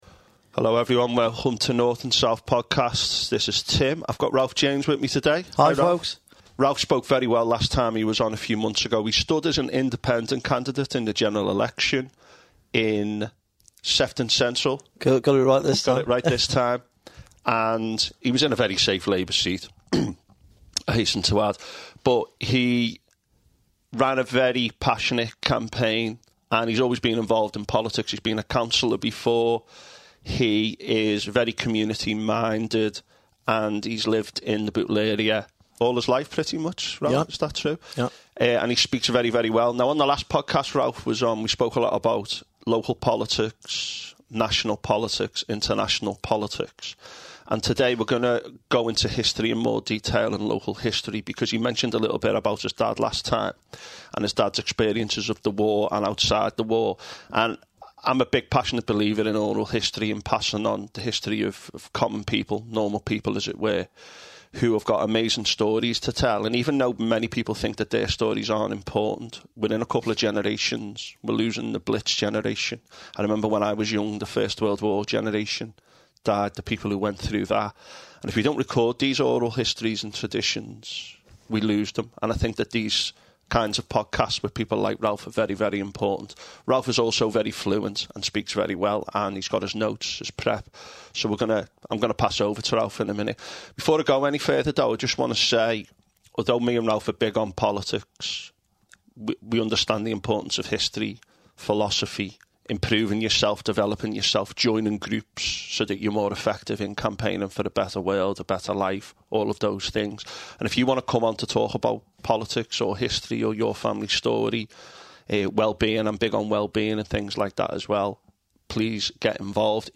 We chat about - Life as a Captain’s Steward.